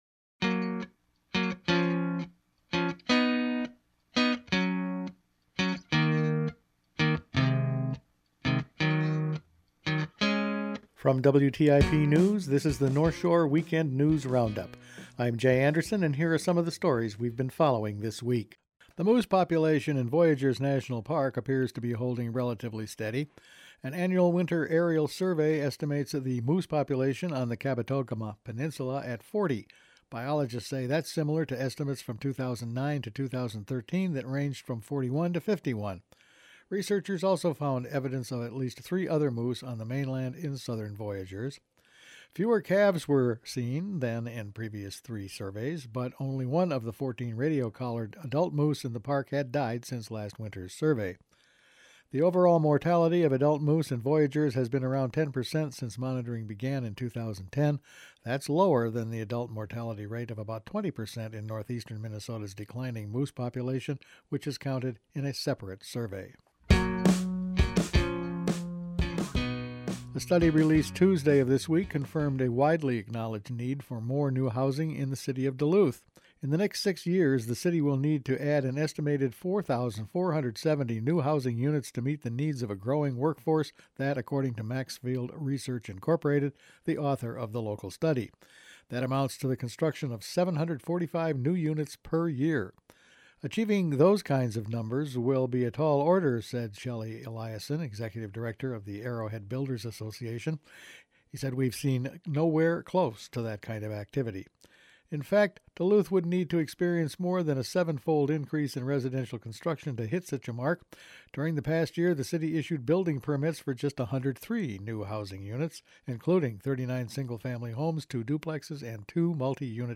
Each week the WTIP news team puts together a roundup of the week's news. Voyageurs Park moose population remains steady, Duluth needs more housing – much more, bridge work makes for difficult driving to and from Duluth and Superior …all this and more in this week’s news.